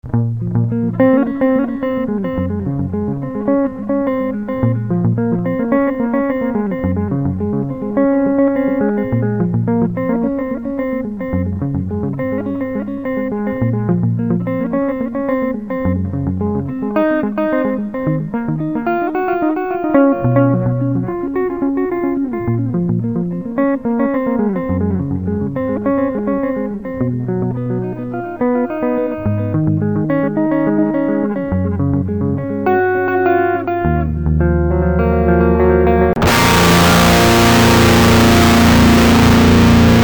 Dream.zip This is a common Dream Theater delay-sound as in "Lifting Shadows..."